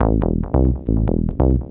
SNTHBASS055_DANCE_140_A_SC3.wav